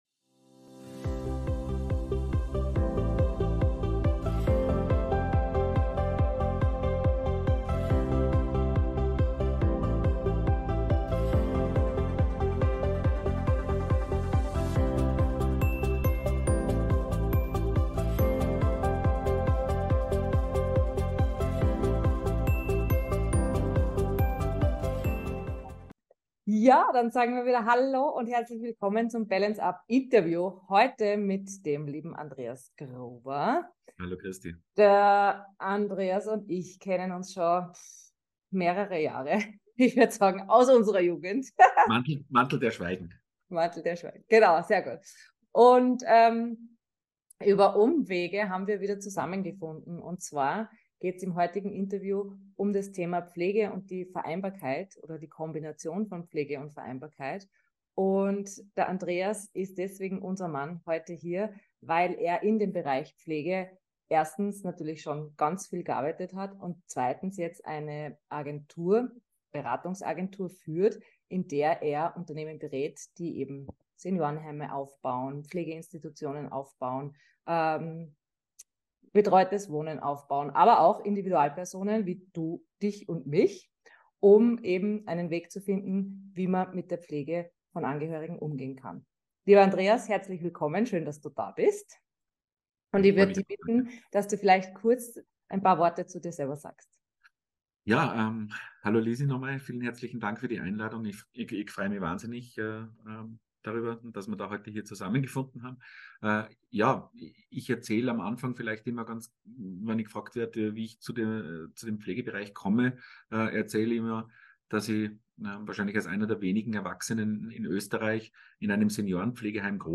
Ein Gespräch über Verantwortung, Systemfehler, emotionale Überforderung – und neue Lösungen, die uns allen helfen können.